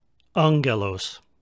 Pronunciation Note: When there is a double Gamma (ɣɣ) in a word, such as in ἄɣɣελος, the first Gamma is pronounced like the ng in sing.